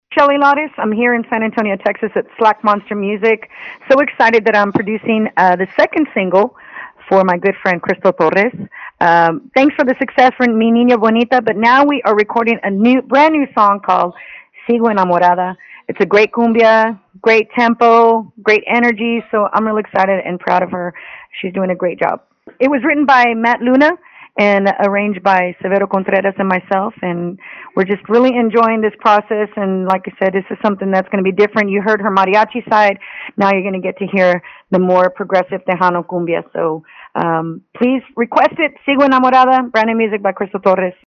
LISTEN: Shelly Lares talks “Sigo Enamorada” with Tejano Nation
shelly-lares-on-sigo-enamorada.mp3